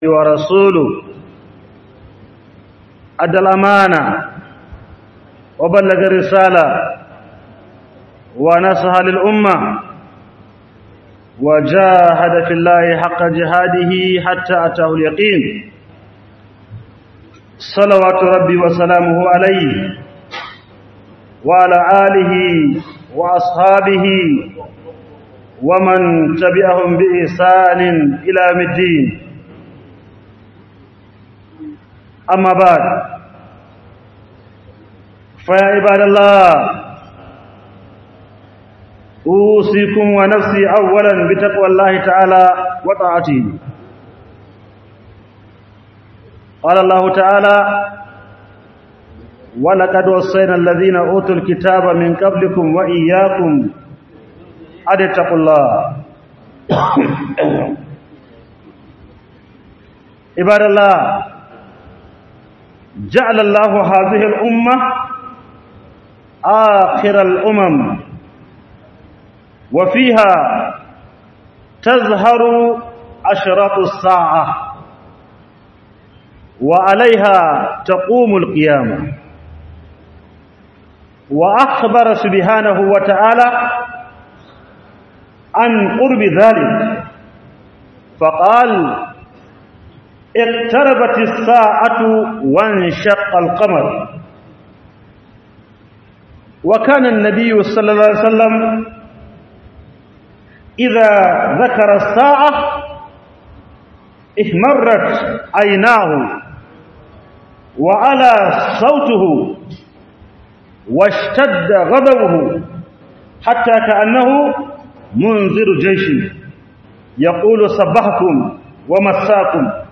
Yin Aiki Dan Allah Shine Mafita - HUDUBA